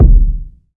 KICK.38.NEPT.wav